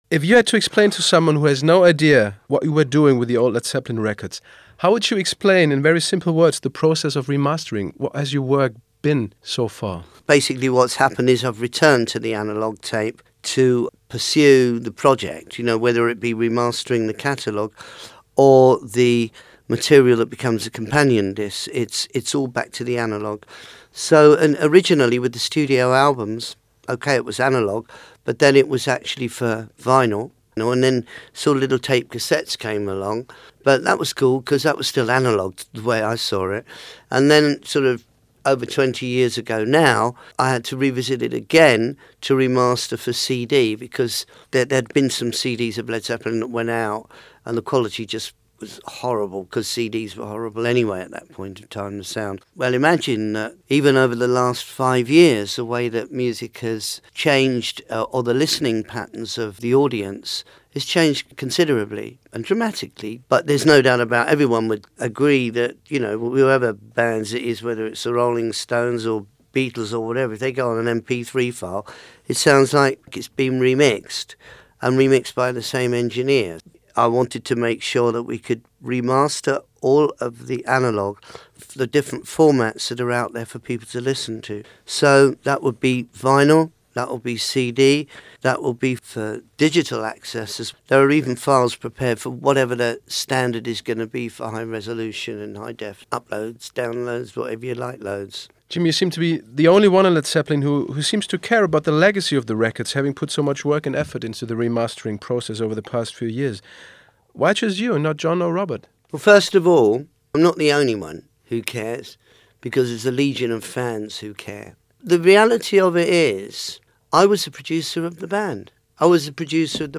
Interview mit Jimmy Page